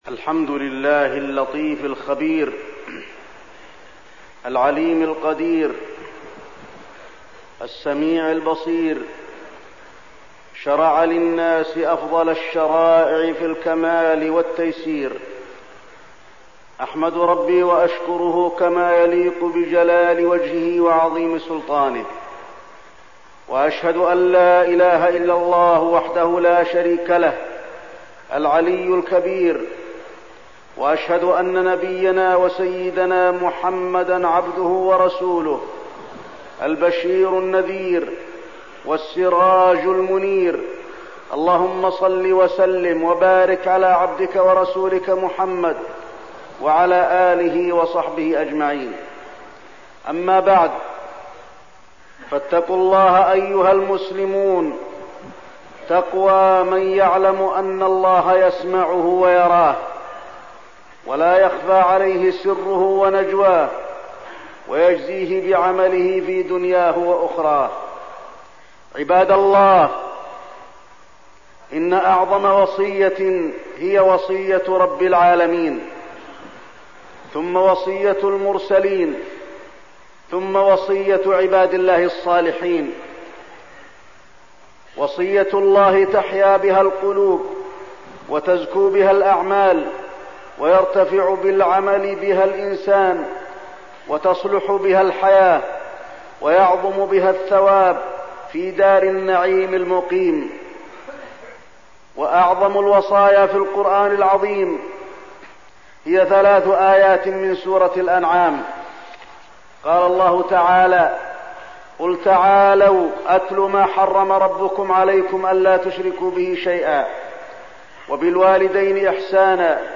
تاريخ النشر ٢٨ رجب ١٤١٨ هـ المكان: المسجد النبوي الشيخ: فضيلة الشيخ د. علي بن عبدالرحمن الحذيفي فضيلة الشيخ د. علي بن عبدالرحمن الحذيفي وصايا سورة الأنعام